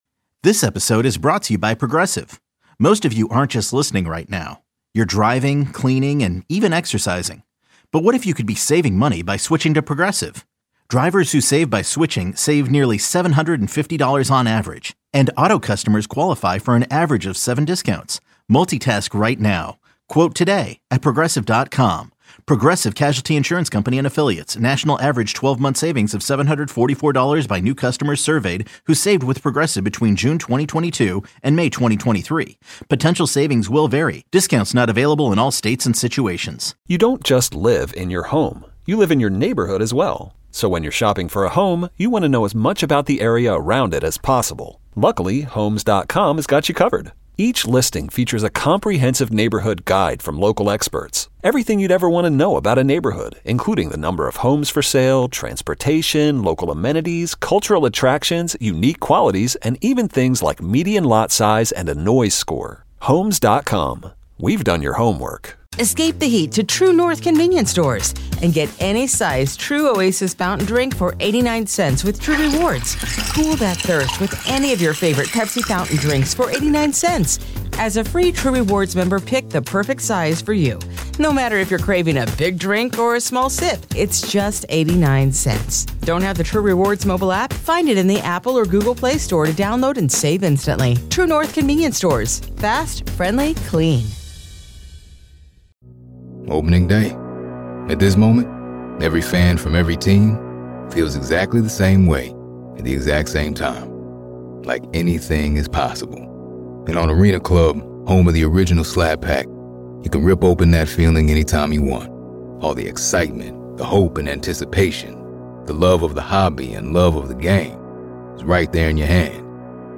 1 Surrounded by Dreams - Sleep Music 1:02:01